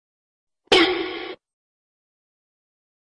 Oi!!! Meme sound effect
Thể loại: Âm thanh meme Việt Nam
Description: “Oi!!! Meme sound effect” là một hiệu ứng âm thanh ngắn vang lên với tiếng “Oi!!!” dứt khoát, tạo cảm giác bất ngờ, hài hước hoặc giật mình.
oi-meme-sound-effect-www_tiengdong_com.mp3